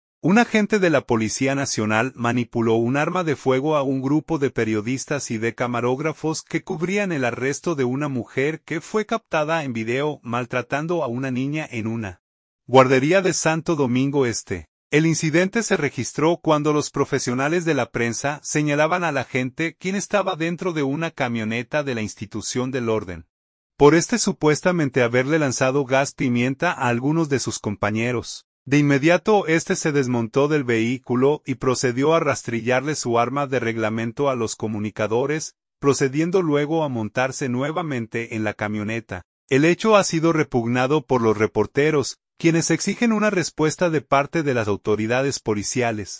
SANTO DOMINGO.– Un agente de la Policía Nacional manipuló un arma de fuego a un grupo de periodistas y de camarógrafos que cubrían el arresto de una mujer que fue captada en video maltratando a una niña en una guardería de Santo Domingo Este.